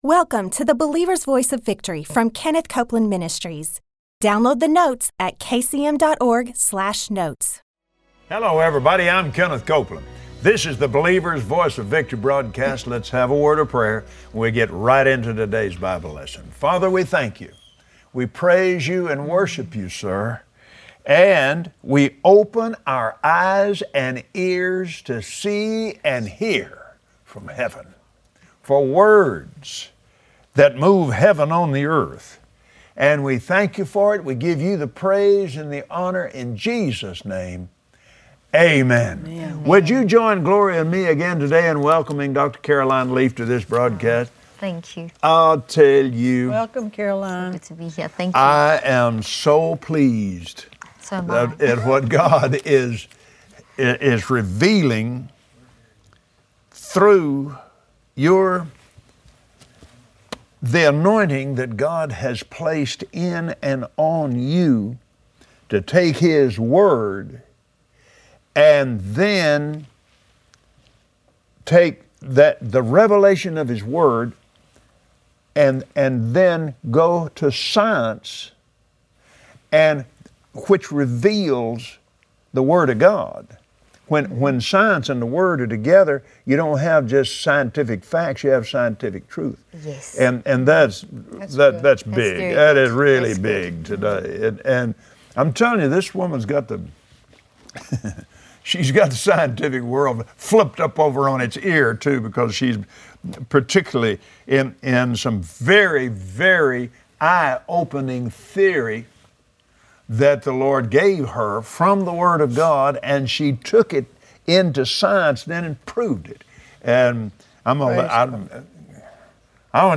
Believers Voice of Victory Audio Broadcast for Wednesday 08/26/2015 When science and scripture come together the result is scientific truth. Today Kenneth and Gloria Copeland welcome again Dr. Caroline Leaf for more insight into her field of cognitive neuroscientist.